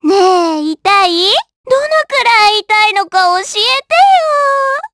Pansirone-Vox_Skill7_jp.wav